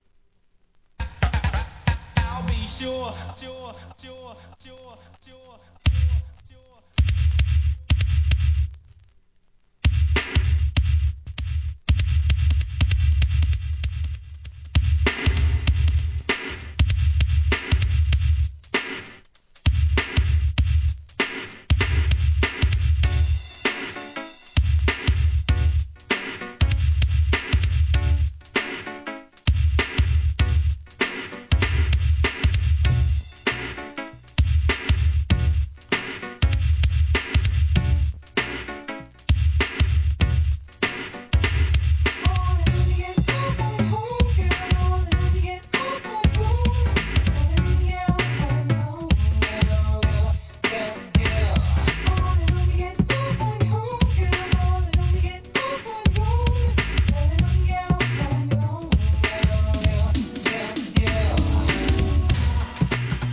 HIP HOP/R&B
NEW JACK SWING!